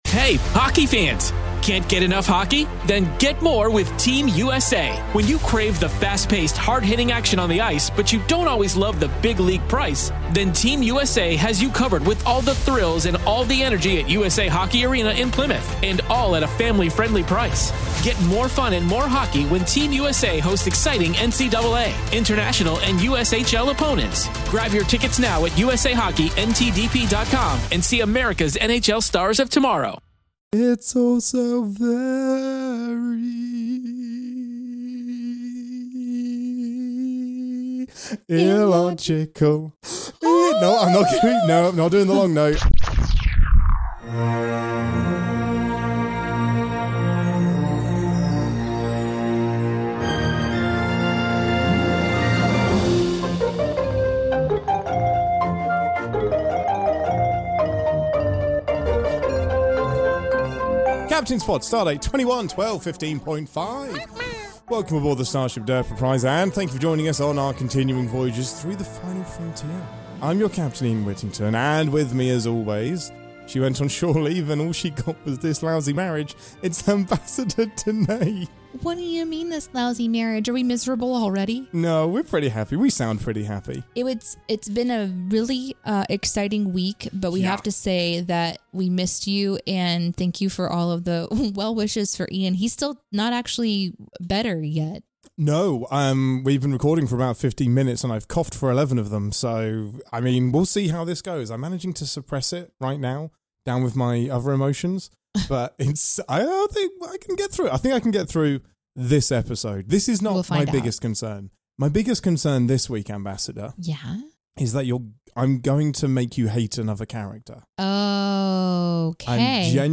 (7:48) 2) The Jefferies Tubes - Bloopers and other goodies that didn’t make it into the show.